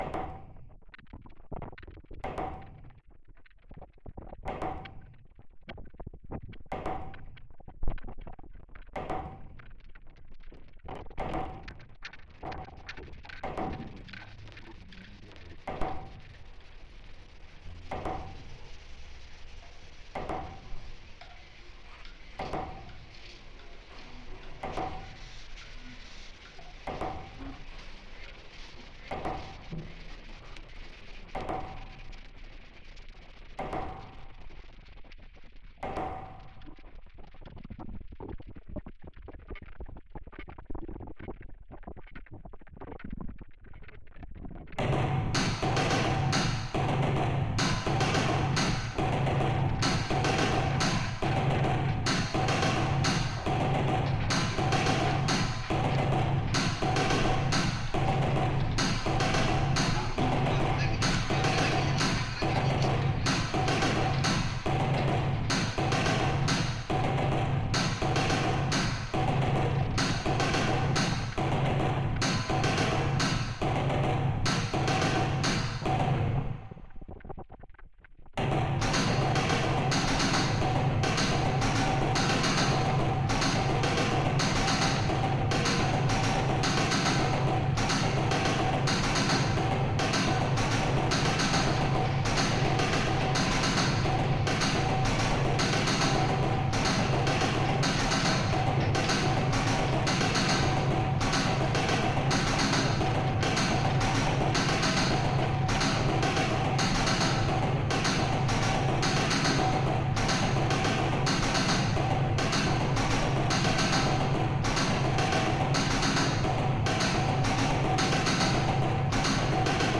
Ambalangoda Mask Museum, Sri Lanka